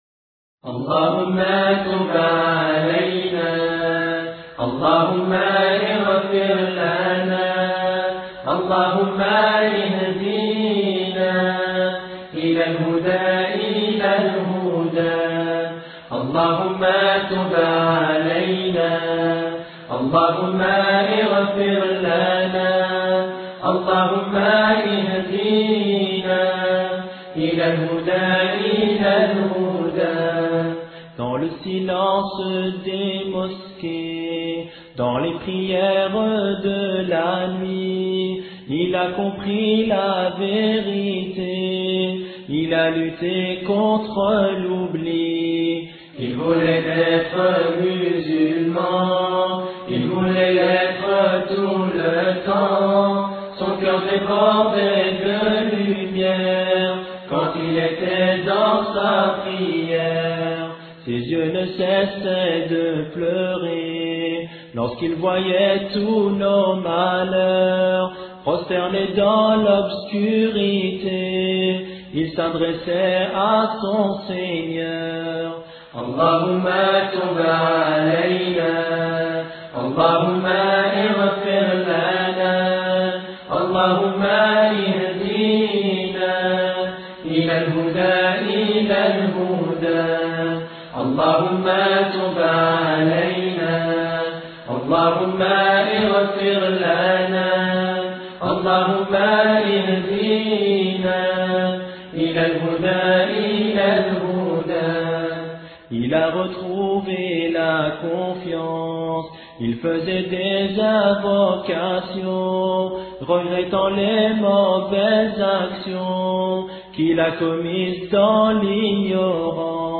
hejab orateur: quelques jeunes période de temps: 00:00:00